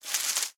leaves1.ogg